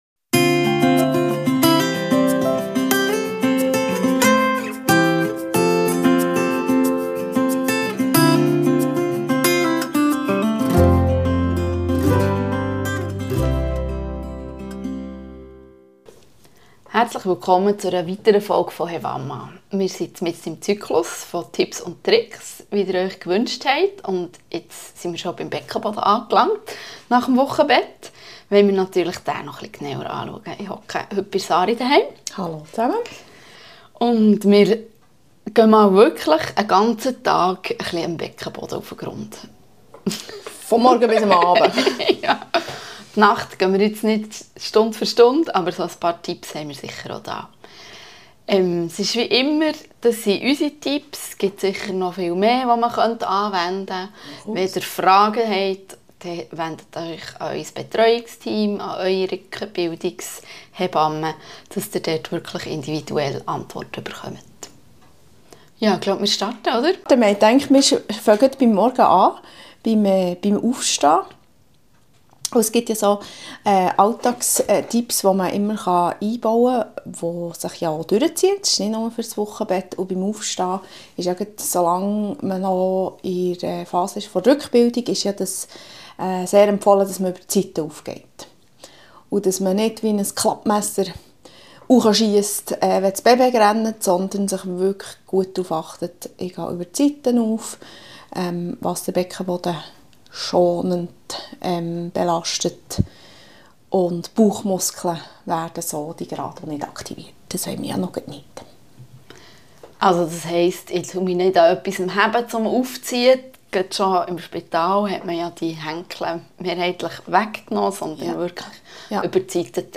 Ihr hört eine 10-minütige Übungseinheit, mit drei konkreten Übungen zur Stärkung des Beckenbodens.